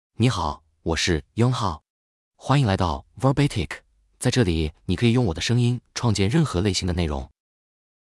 Yunhao — Male Chinese (Mandarin, Simplified) AI Voice | TTS, Voice Cloning & Video | Verbatik AI
Yunhao is a male AI voice for Chinese (Mandarin, Simplified).
Voice sample
Listen to Yunhao's male Chinese voice.
Yunhao delivers clear pronunciation with authentic Mandarin, Simplified Chinese intonation, making your content sound professionally produced.